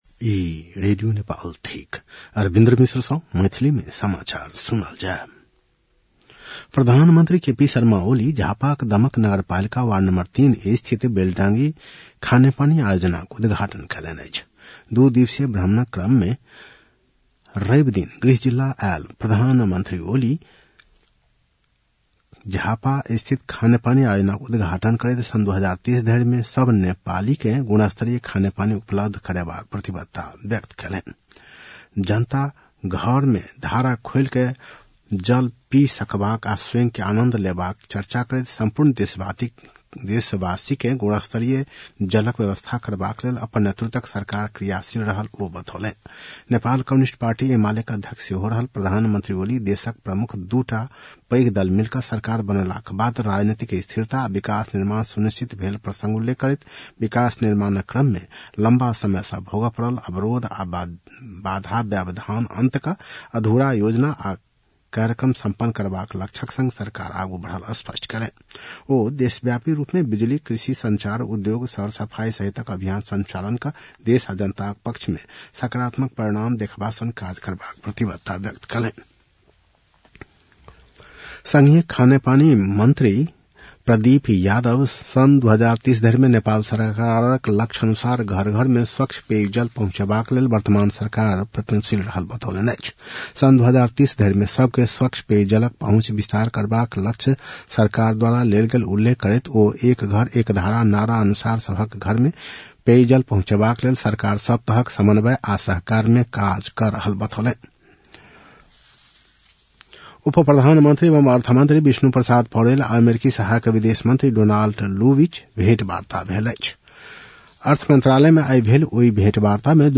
मैथिली भाषामा समाचार : २५ मंसिर , २०८१